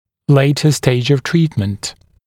[‘leɪtə steɪʤ əv ‘triːtmənt][‘лэйтэ стэйдж ов ‘три:тмэнт]поздняя стадия лечения